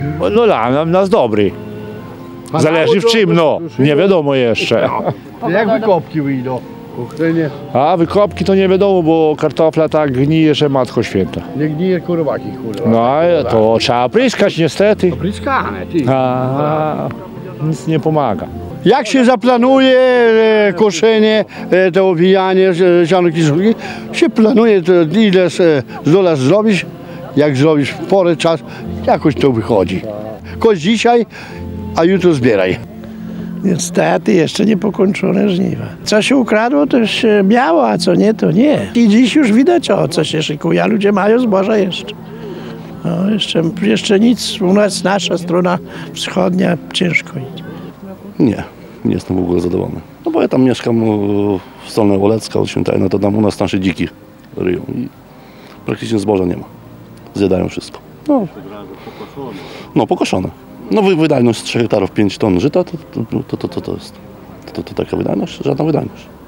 Dożynki powiatu suwalskiego odbyły się w niedzielę (03.09) w Dowspudzie koło Raczek.
rolnicy.mp3